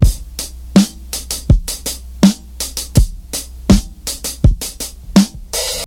• 82 Bpm Modern Drum Loop E Key.wav
Free breakbeat sample - kick tuned to the E note. Loudest frequency: 1387Hz
82-bpm-modern-drum-loop-e-key-zFr.wav